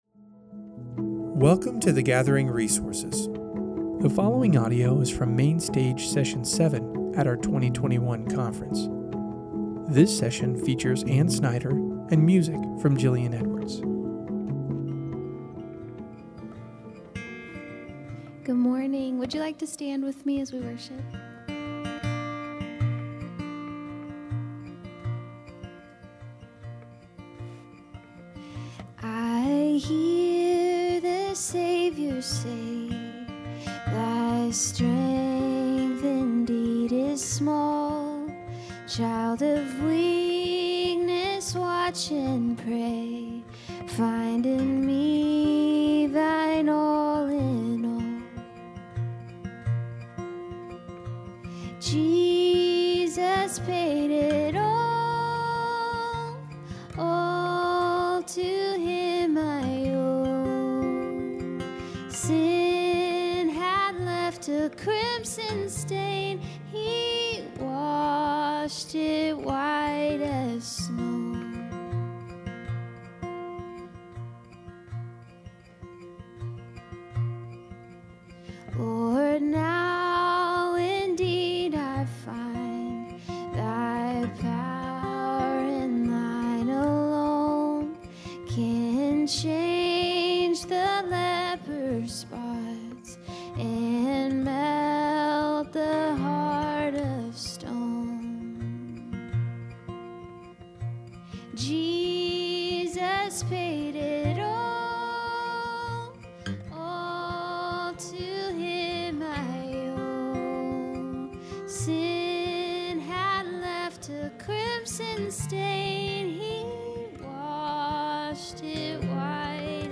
The following audio is from Main Stage Session 7 at our 2021 conference.